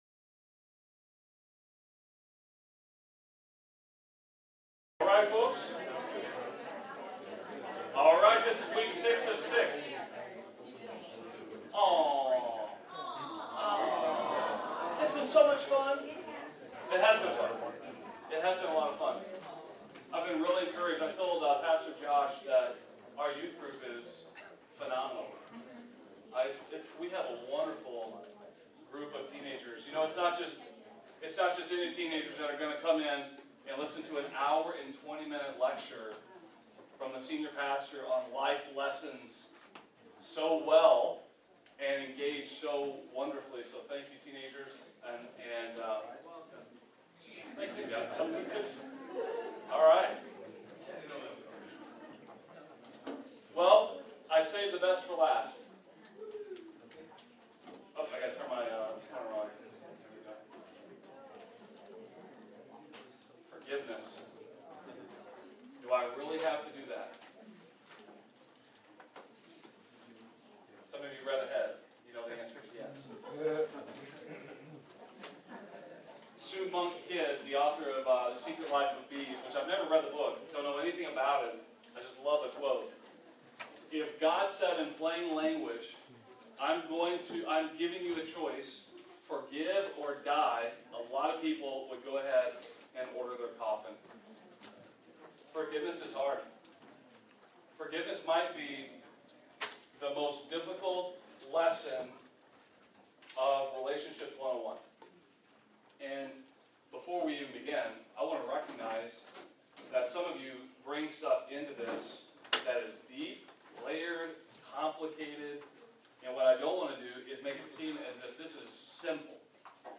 A message from the series "Relationships 101."